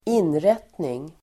Uttal: [²'in:ret:ning]